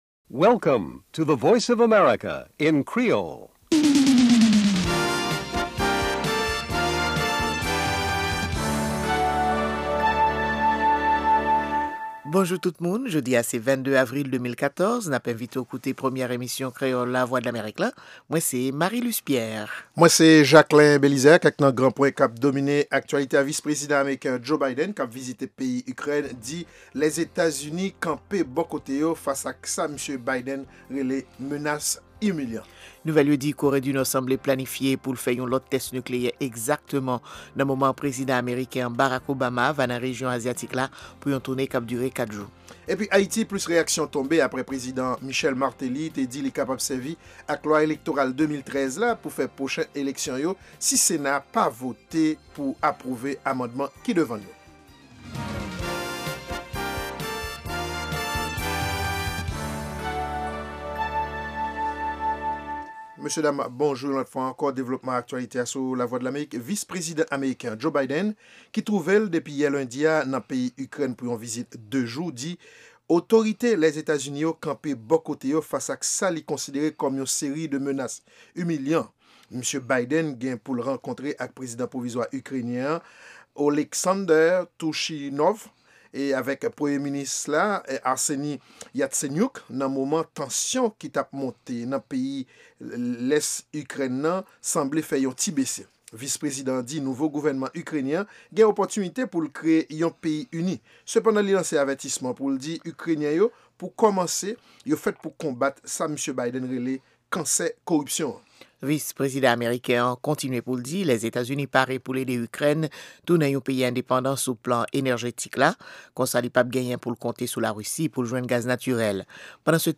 Se premye pwogram jounen an ki gen ladan dènye nouvèl sou Lèzetazini, Ayiti ak rès mond la. Pami segman yo genyen espò, dyasporama ak editoryal la.